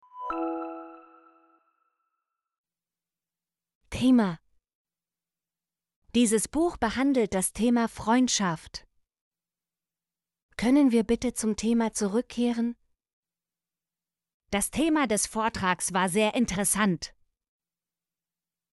thema - Example Sentences & Pronunciation, German Frequency List